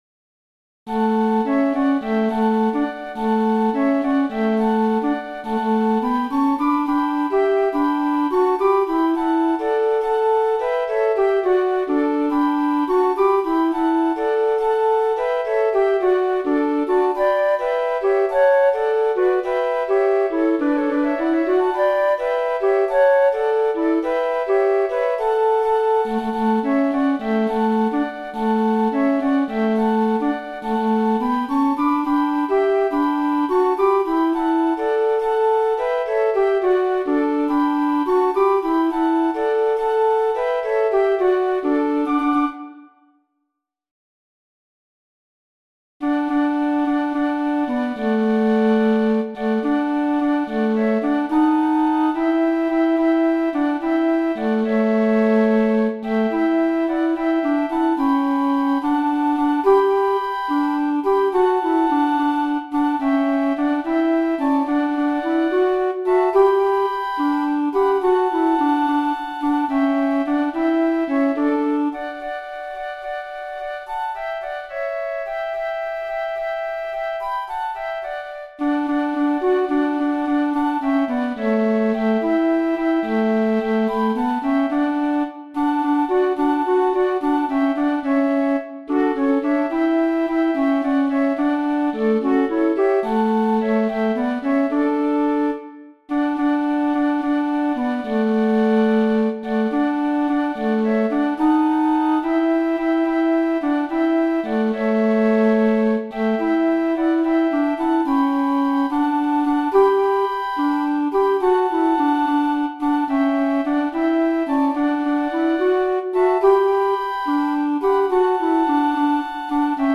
Moderato
2 C flutes
1 Alto flutes